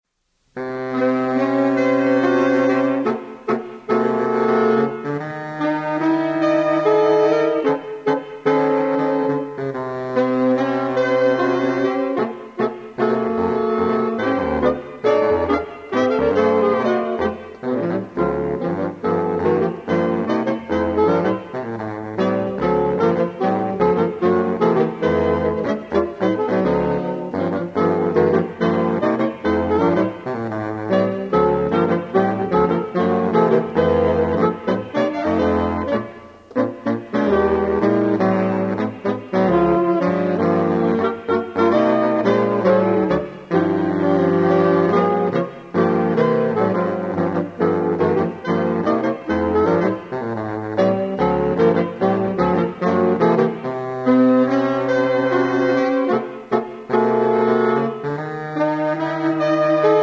live recordings
baritone saxophone
tenor saxophone
alto saxophone
soprano and alto saxophone